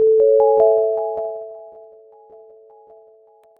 mail.ogg